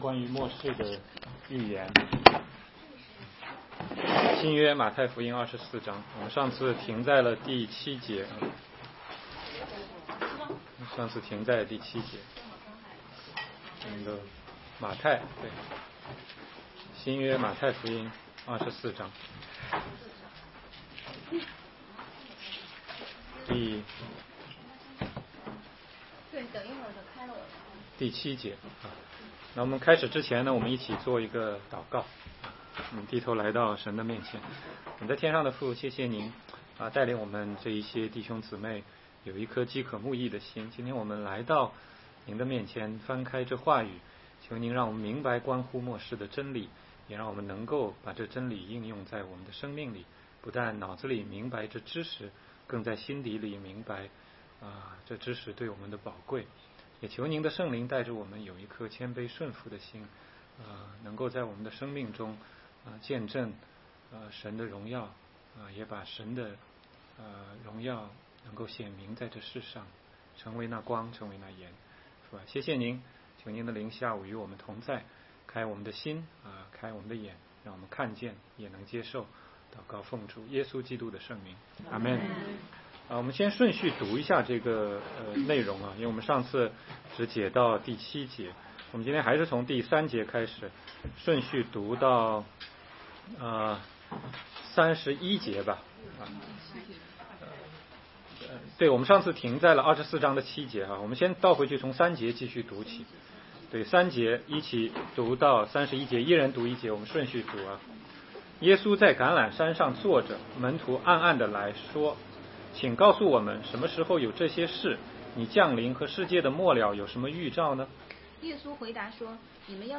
16街讲道录音 - 马太福音24章3-41节：主预言末世